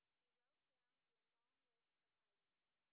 sp11_white_snr0.wav